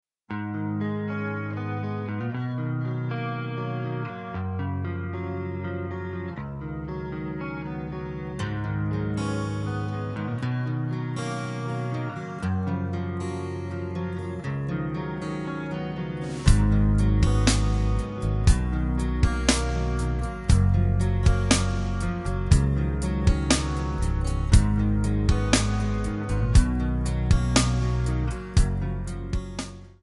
C#
Backing track Karaoke
Pop, Rock, 2000s